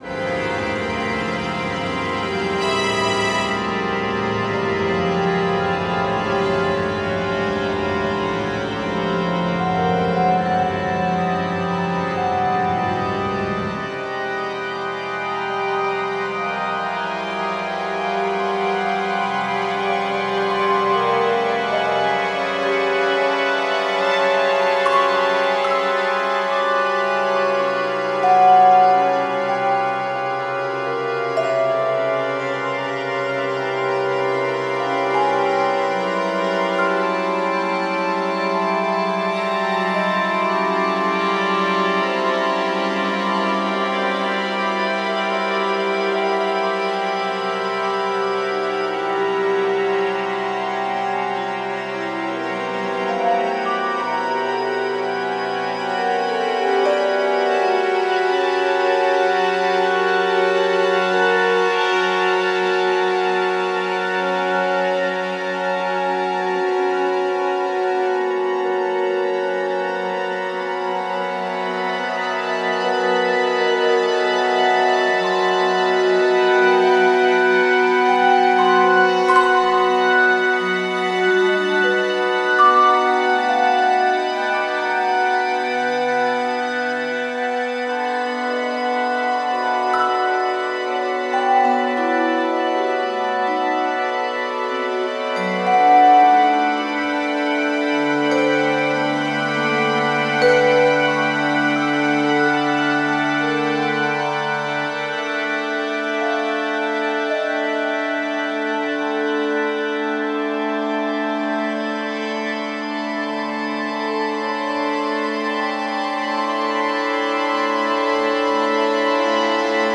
Cloud Triptych for large orchestra
The specific focus of my research is music for acoustic instruments.